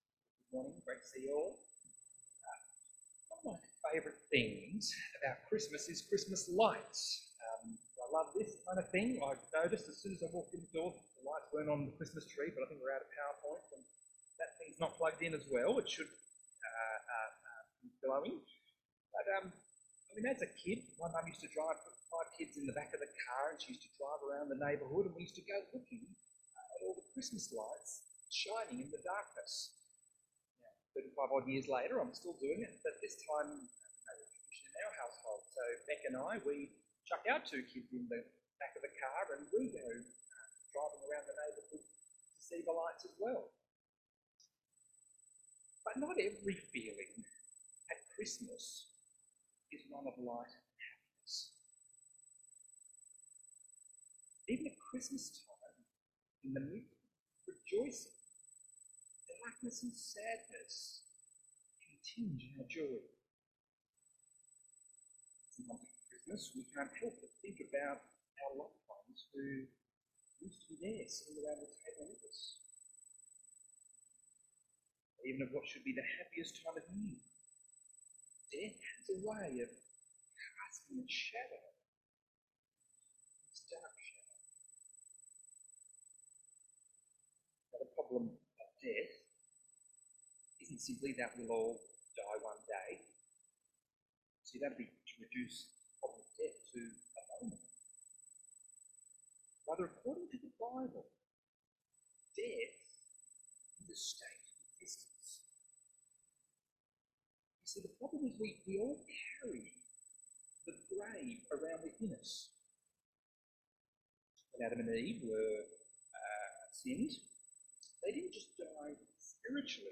Luke 1:57-79 Service Type: 10am Service « Work & Rest